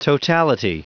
Prononciation du mot totality en anglais (fichier audio)
Prononciation du mot : totality